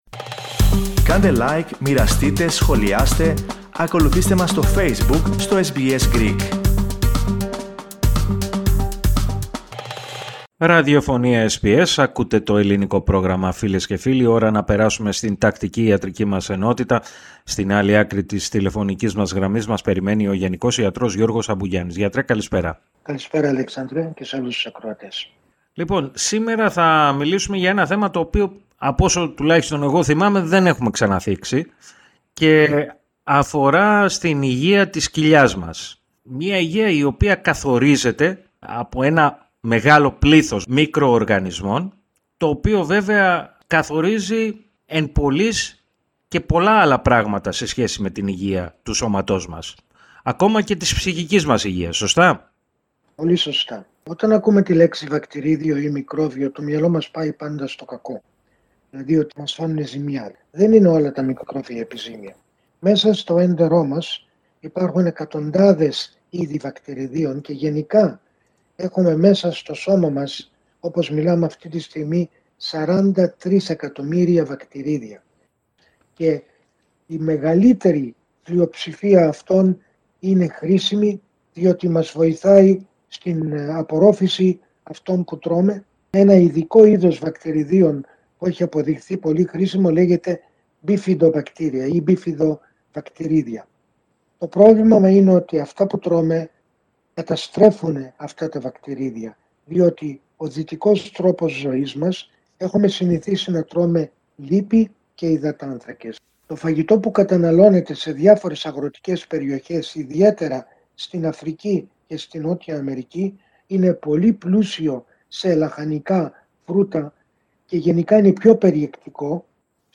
συνομιλία